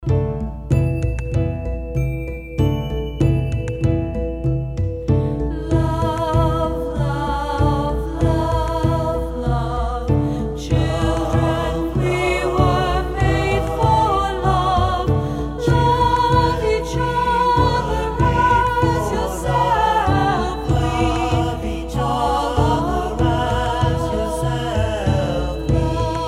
Traditional Campfire Song